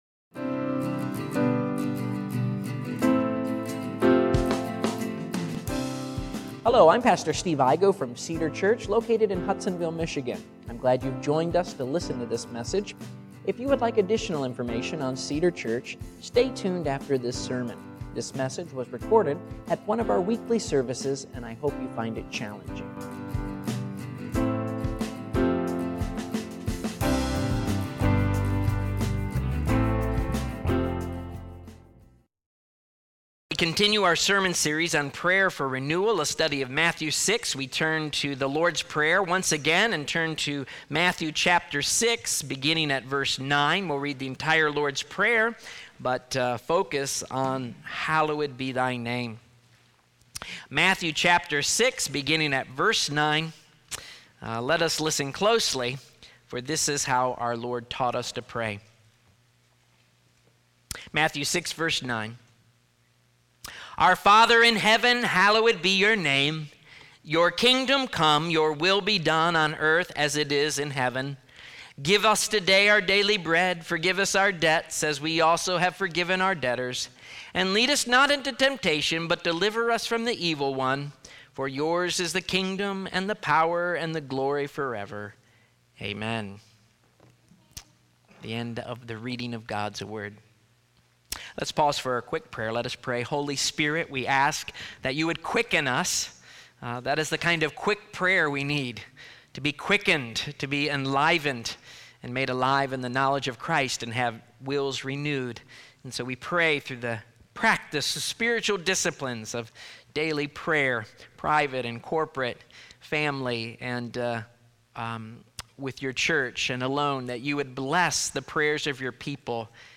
Sermons | Cedar Church